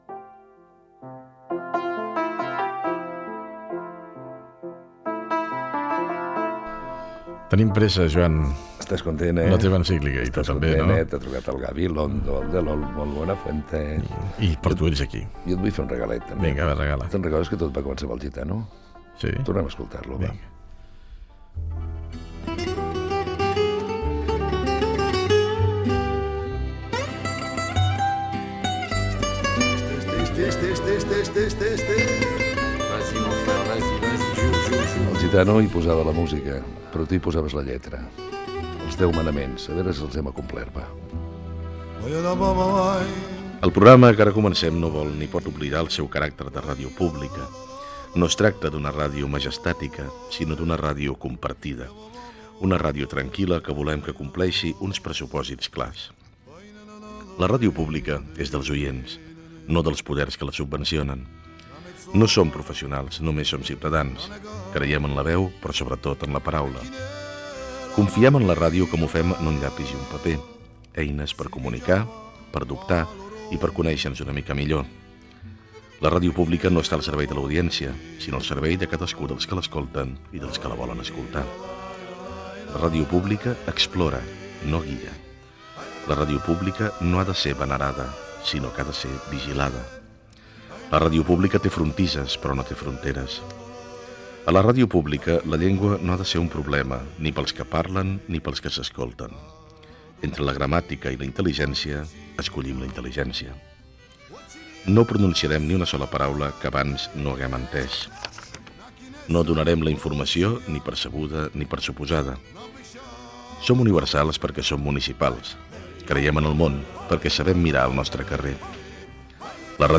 Info-entreteniment
Fragment extret de l'arxiu sonor de COM Ràdio.